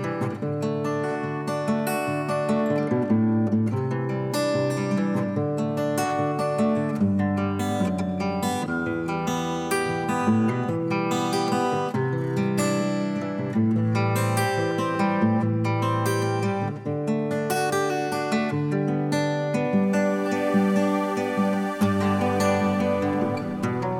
End Cut Down Pop (1980s) 4:48 Buy £1.50